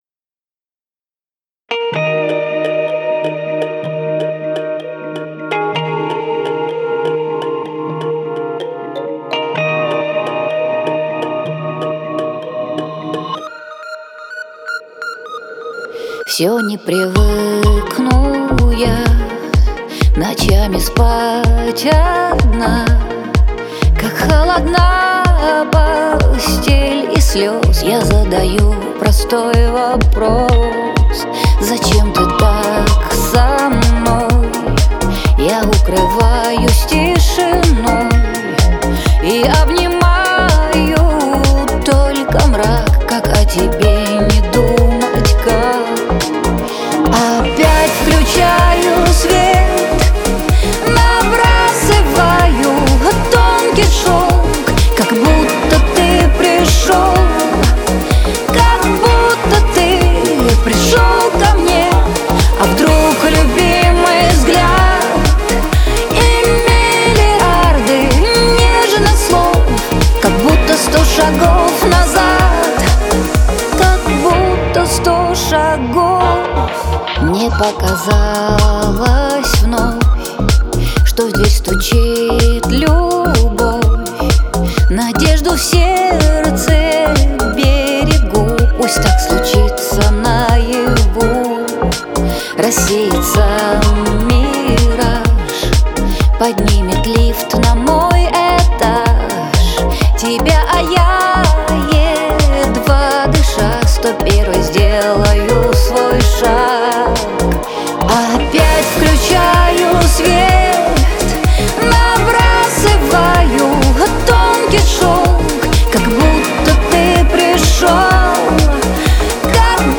это проникновенная песня в жанре поп